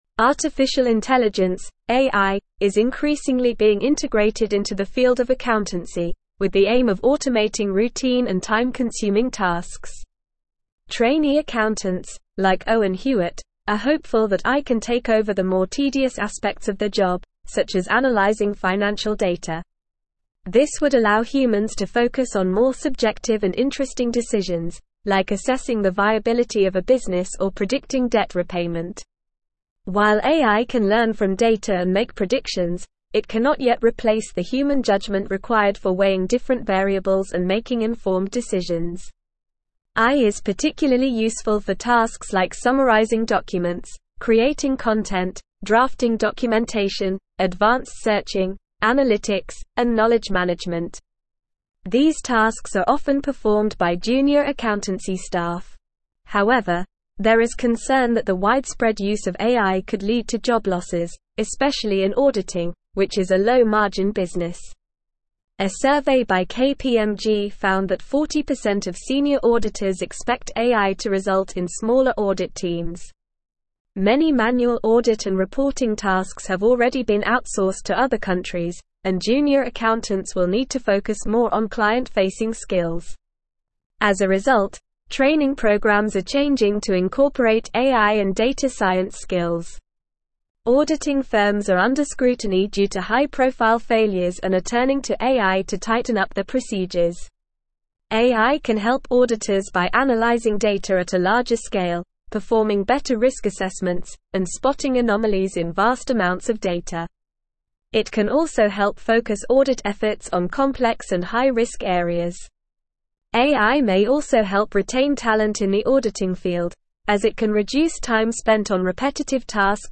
Normal
English-Newsroom-Advanced-NORMAL-Reading-AI-Revolutionizing-Accountancy-Streamlining-Processes-and-Improving-Audits.mp3